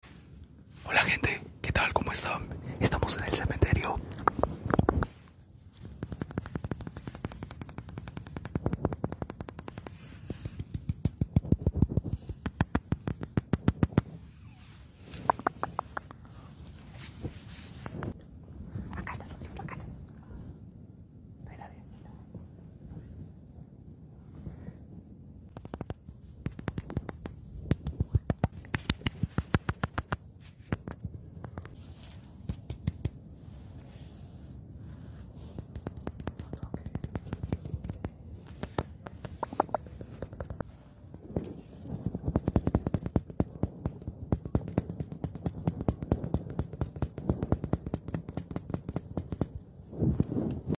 Asmr en el cementerio de sound effects free download
Asmr en el cementerio de noche